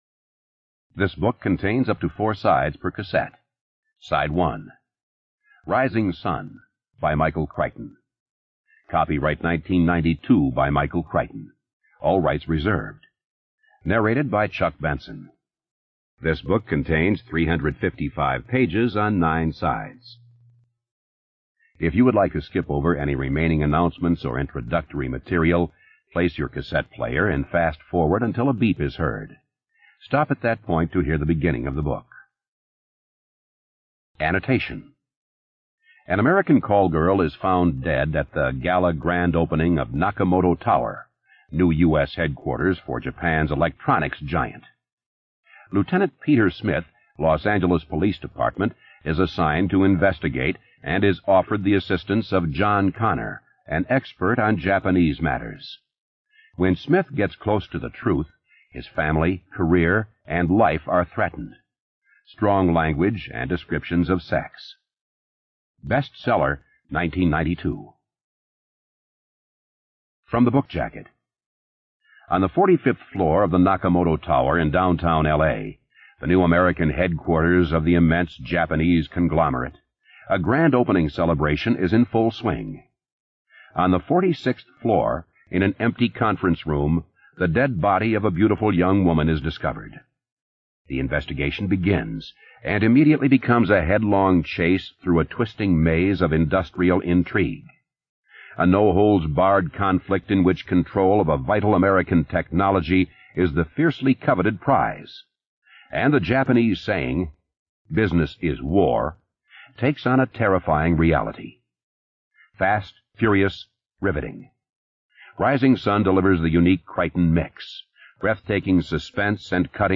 ЖанрКниги на языках народов Мира